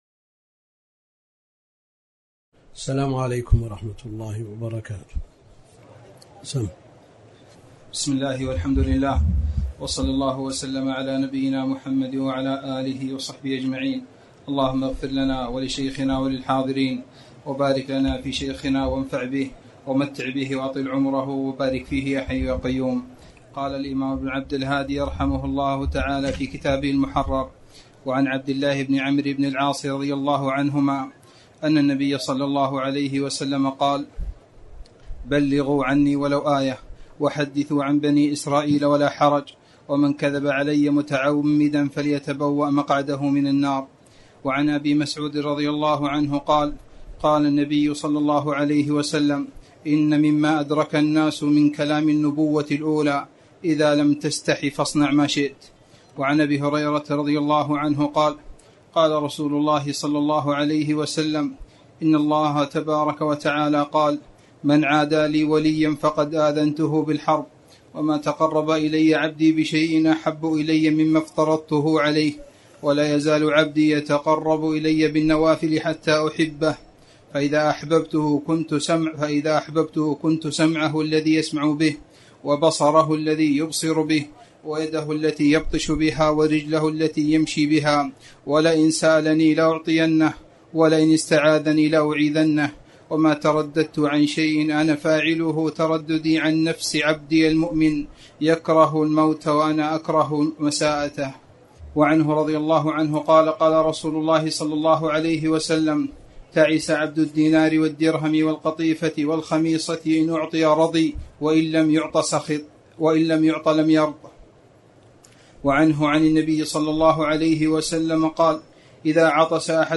تاريخ النشر ١٢ ذو القعدة ١٤٣٨ هـ المكان: المسجد الحرام الشيخ: فضيلة الشيخ د. عبد الكريم بن عبد الله الخضير فضيلة الشيخ د. عبد الكريم بن عبد الله الخضير كتاب الجامع